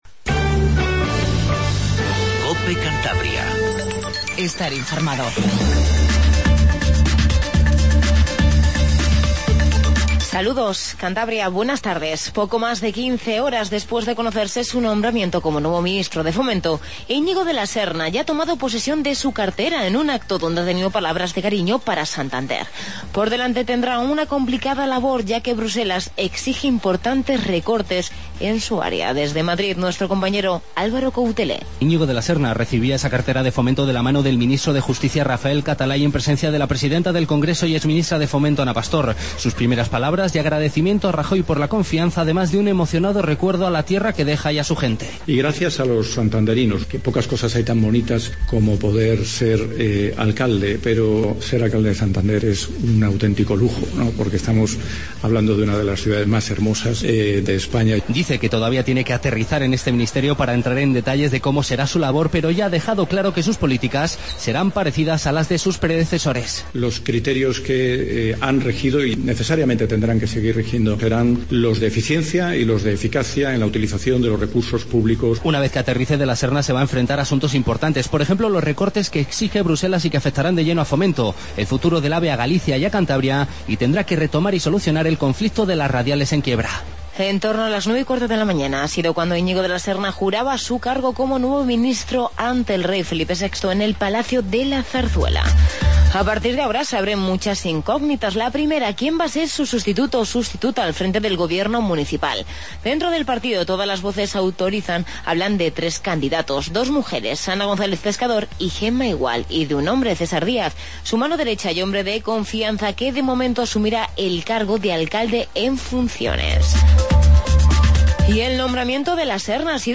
AUDIO: Noticias